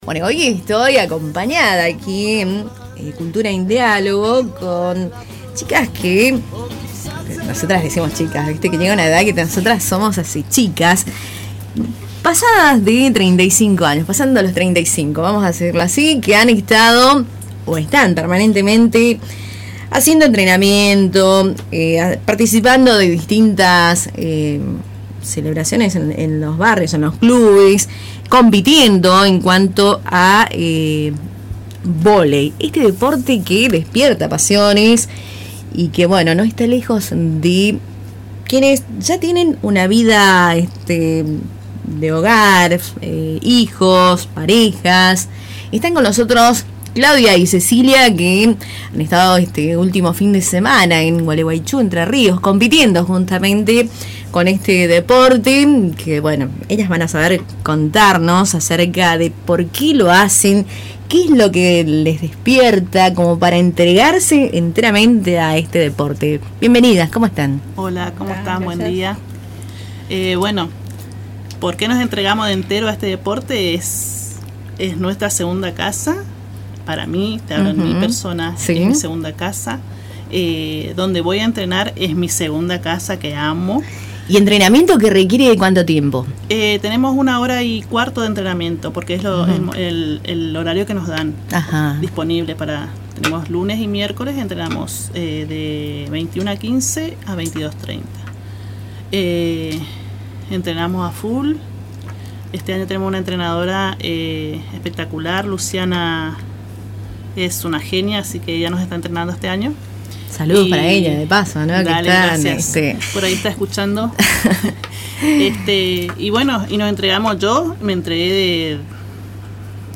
Escuchá la entrevista realizada en Radio Tupambaé: https